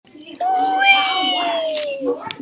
Funny Weeee Sound Effect Free Download
Funny Weeee